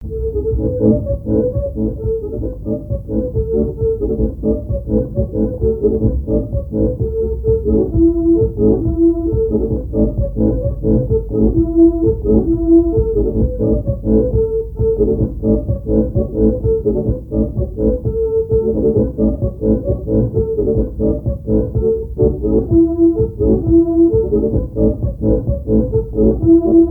branle
Chants brefs - A danser
Répertoire à l'accordéon diatonique
Pièce musicale inédite